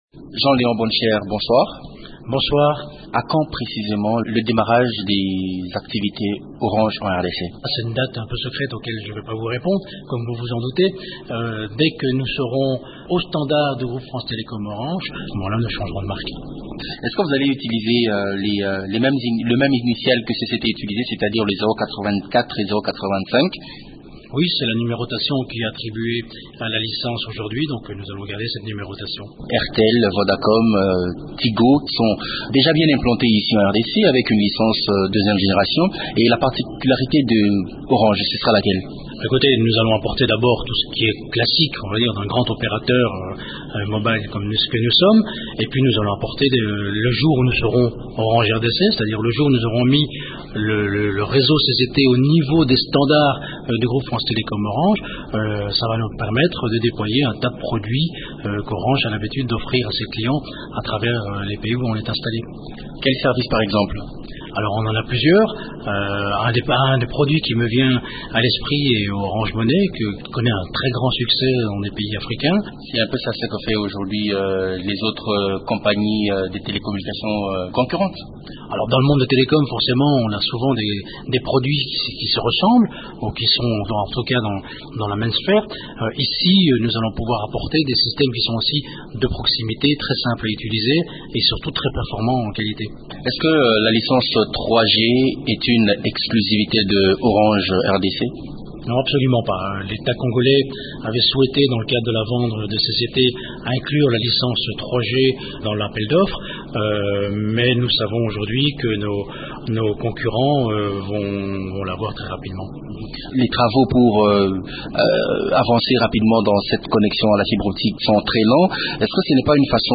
Le groupe France Télécom Orange a racheté l’opérateur de téléphonie mobile Congo Chine Telecom (CCT) depuis octobre 2011.